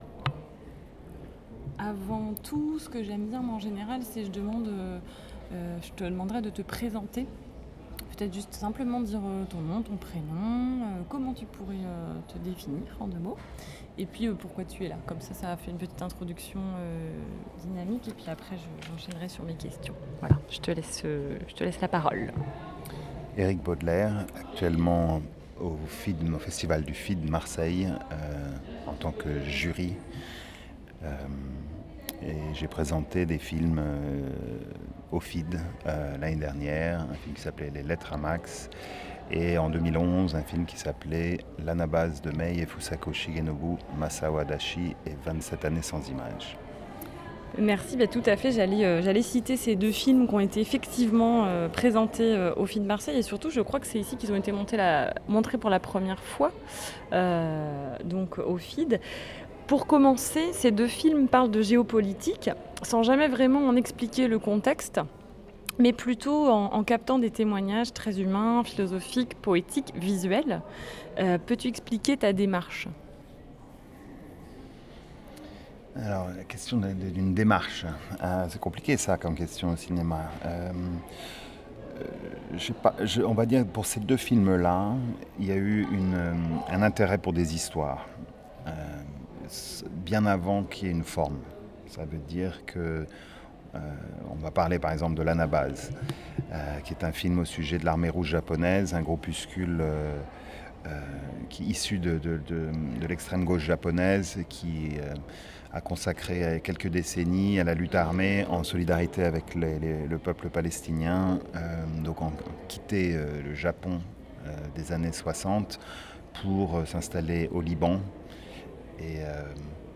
Documents joints Podcast de l’émission du 12 et 26 septembre 2015 (MP3 - 138.6 Mo) Interview intégrale d’Eric Baudelaire (MP3 - 44.3 Mo) SALON D'ECOUTE Aucun audio !
mp3_Interview_integrale_Eric_Baudelaire.mp3